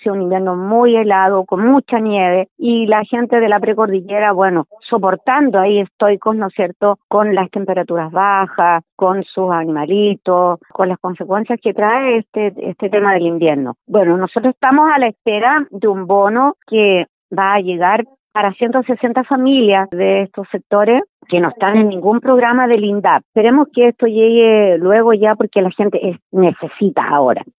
La alcaldesa de Puyehue, María Ximena Núñez, llamó al gobierno a traspasar fondos para ir en ayuda de los afectados en materia productiva, luego de la caída de nieve.